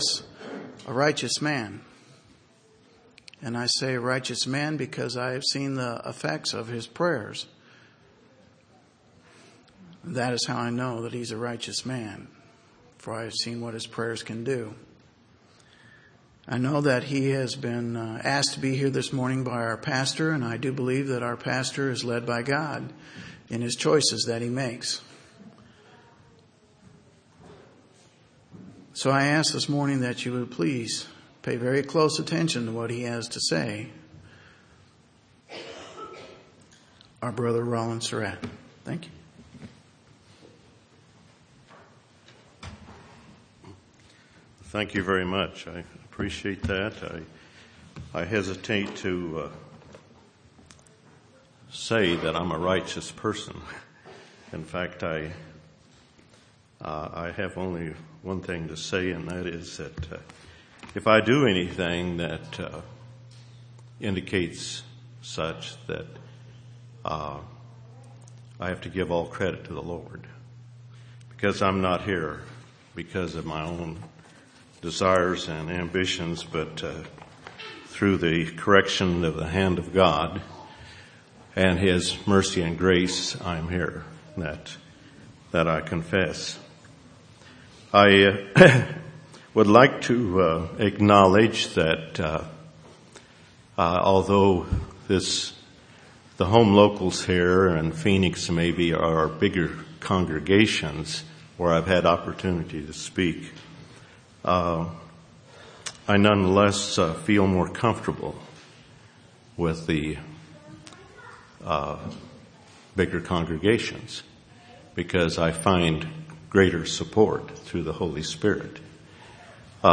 This sermon shows how to become perfect and how to follow Christ. By his grace you might be perfect in his eyes.